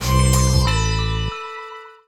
Dr. Dre Synth.wav